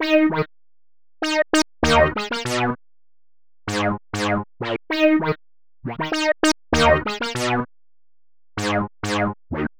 Hands Up - 303 Synth.wav